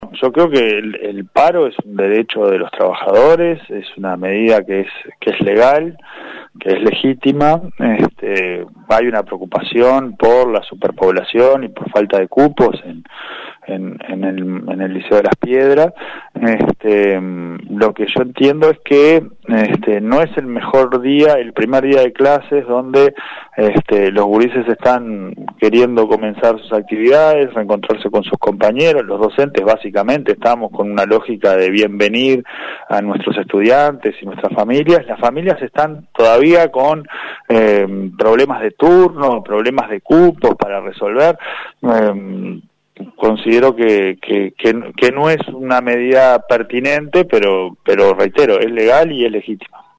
El presidente designado de la Anep, Pablo Caggiani, se refirió en una entrevista con 970 Noticias al paro estipulado para este miércoles en el liceo de Las Piedras, día en el cual comienzan sus actividades los estudiantes.
Caggiani-sobre-Paro.mp3